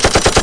Autorifle1.mp3